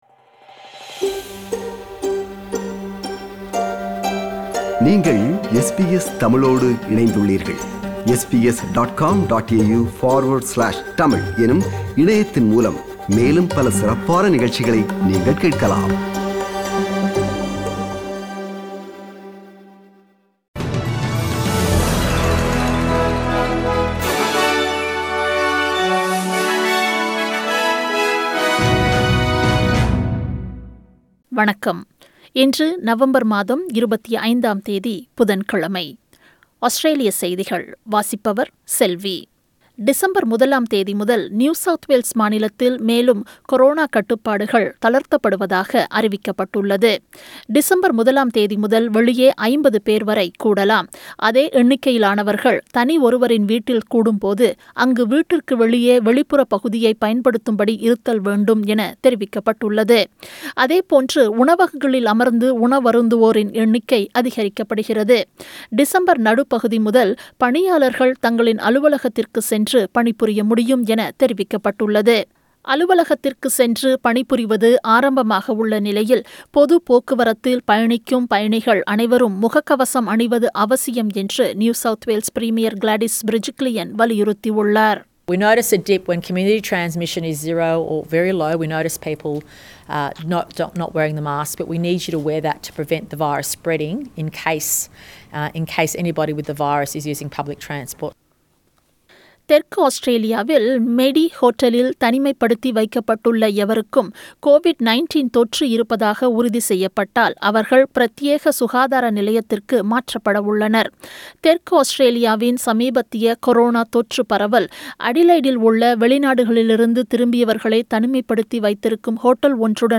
SBS தமிழ் ஒலிபரப்பின் இன்றைய (புதன்கிழமை 25/11/2020) ஆஸ்திரேலியா குறித்த செய்திகள்.